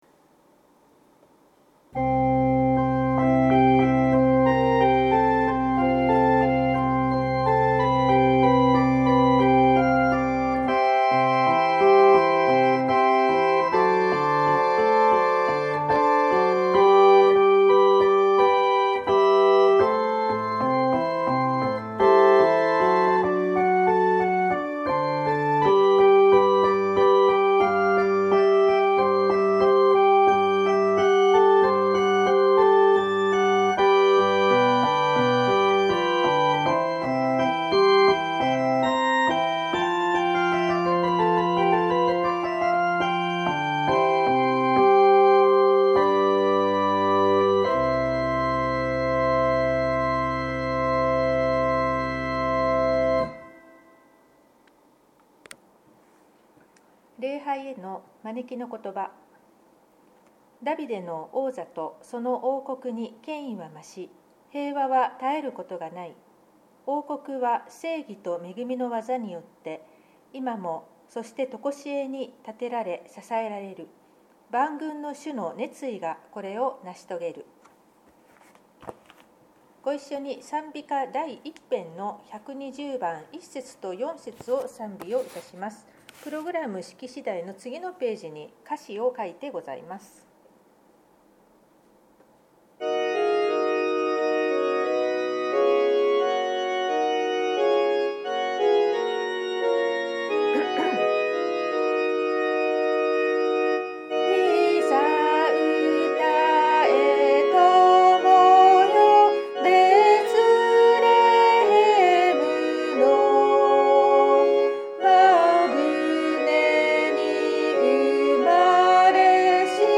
聖日礼拝のご案内（受難節第2主日礼拝） – 日本基督教団 花小金井教会
左下の三角形のアイコンをクリックすることにより礼拝の音声を聞くことができます。